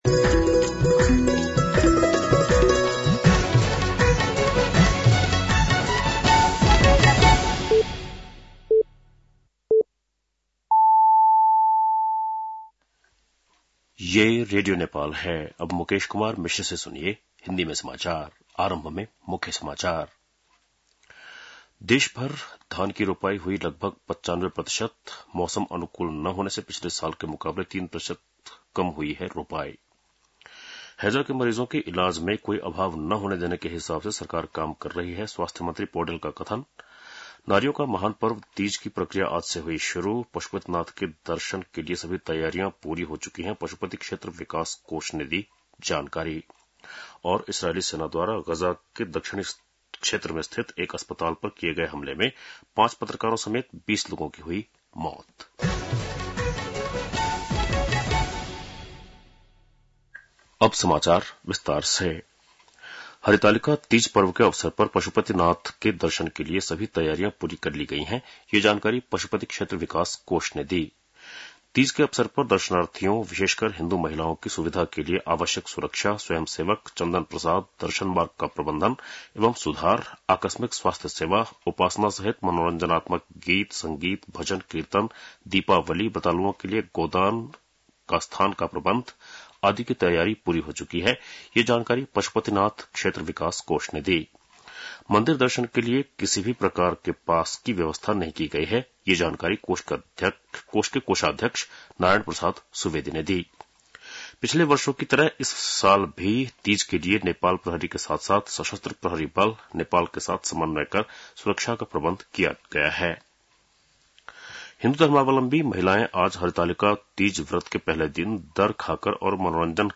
बेलुकी १० बजेको हिन्दी समाचार : ९ भदौ , २०८२
10-PM-Hindi-NEWS-5-09.mp3